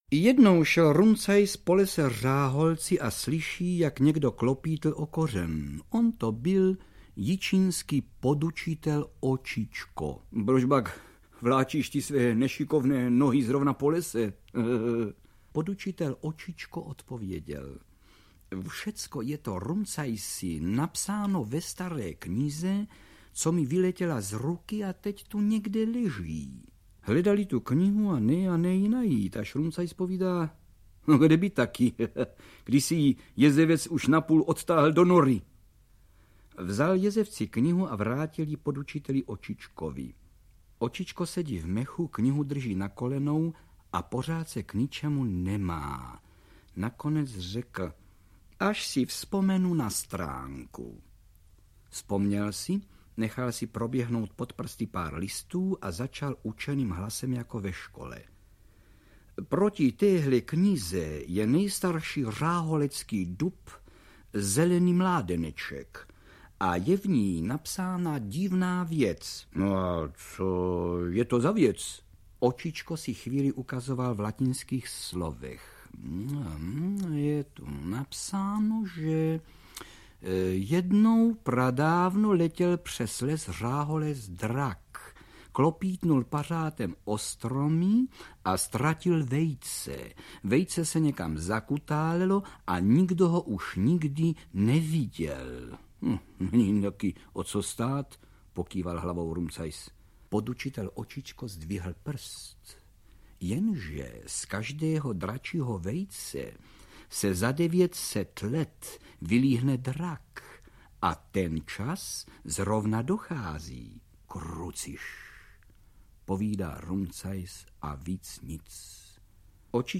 Pohádkové postavičky Václava Čtvrtka - výběr z Pohádek z pařezové chaloupky Křemílka a Vochomůrky a pohádek O loupežníku Rumcajsovi v nezaměnitelném podání Karla Högera a Jiřiny Bohdalové
Ukázka z knihy
• InterpretJiřina Bohdalová, Karel Höger